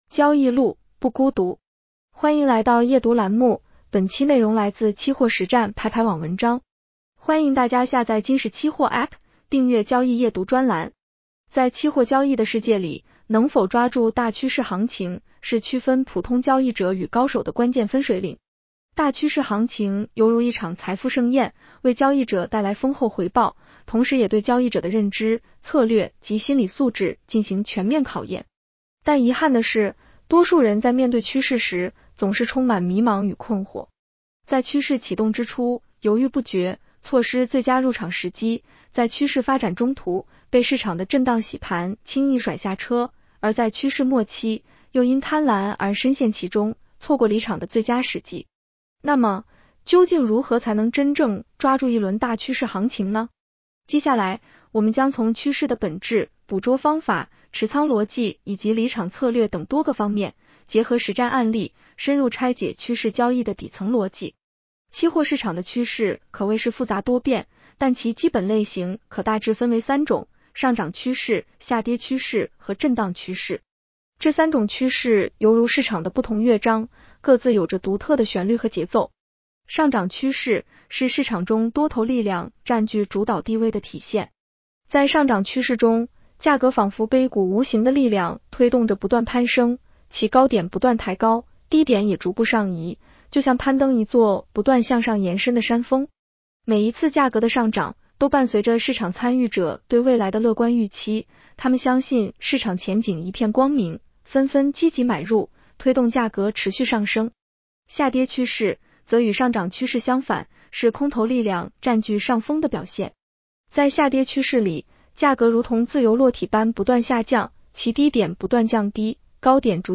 女声普通话版 下载mp3 一、看懂趋势本质，把握底层逻辑 在期货交易的世界里，能否抓住大趋势行情，是区分普通交易者与高手的关键分水岭。